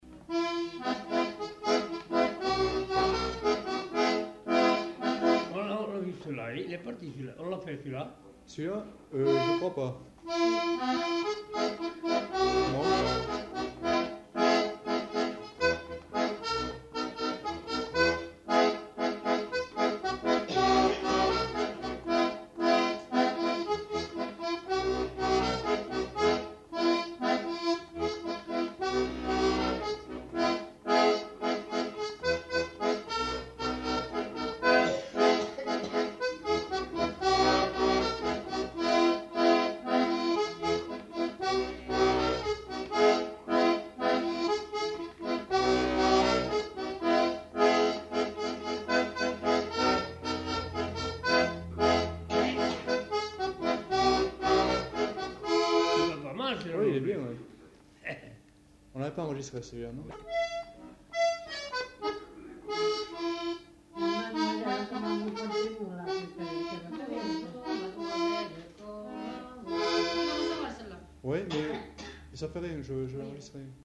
Genre : morceau instrumental
Instrument de musique : accordéon diatonique
Danse : rondeau
Ecouter-voir : archives sonores en ligne